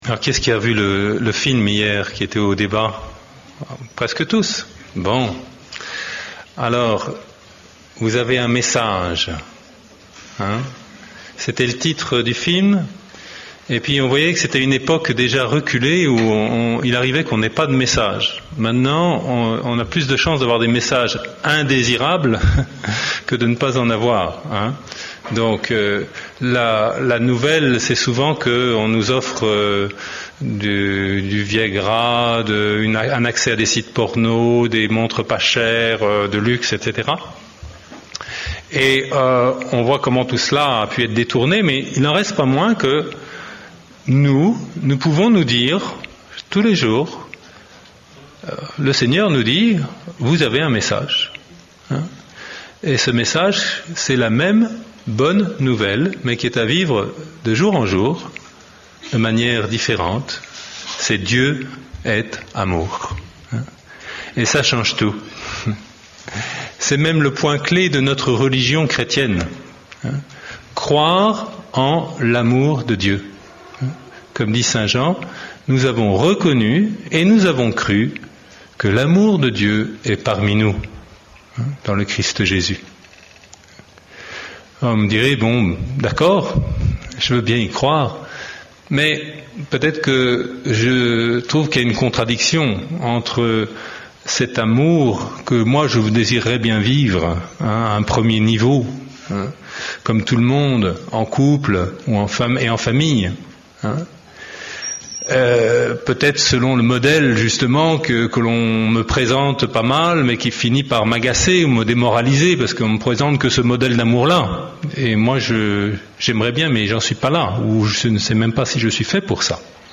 Session des familles du 22 au 27 juillet 2006.